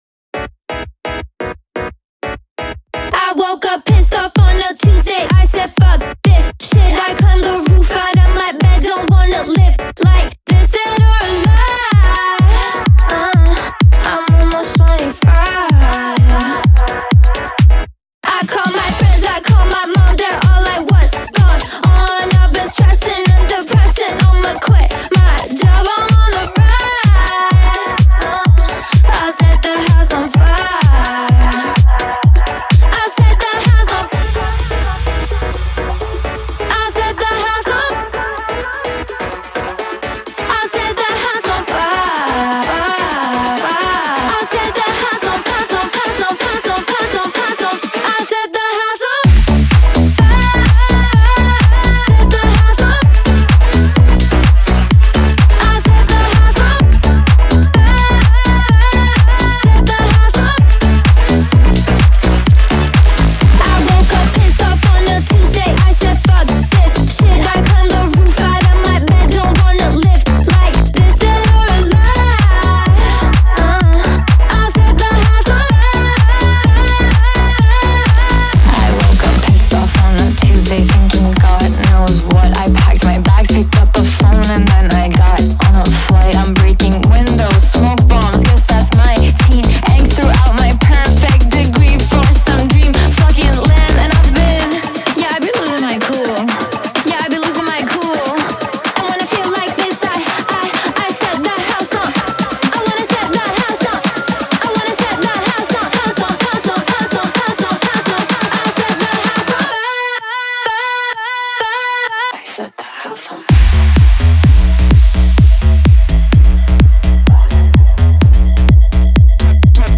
BPM127-127
Audio QualityPerfect (High Quality)
Electro Pop song for StepMania, ITGmania, Project Outfox
Full Length Song (not arcade length cut)